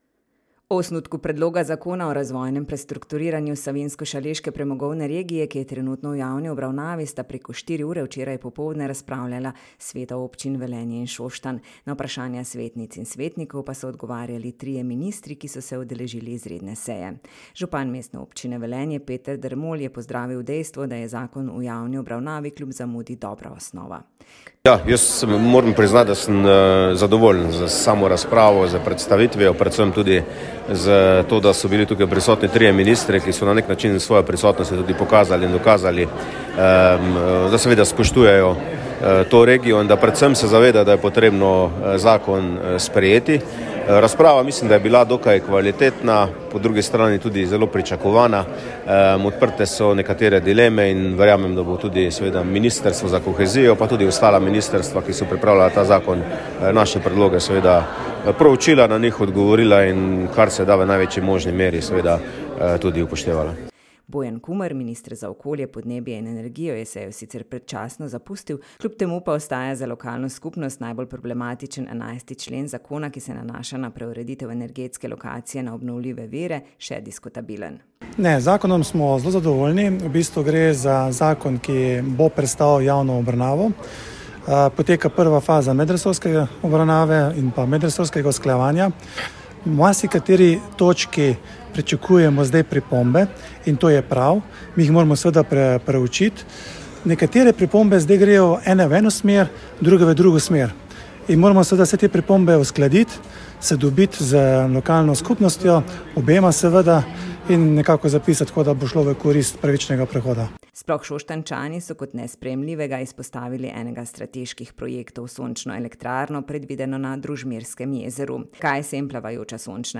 Izredna seja velenjskih in šoštanjskih svetnikov.